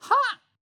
戦闘 掛け声 気合い ボイス 声素材 – Battle Cries Voice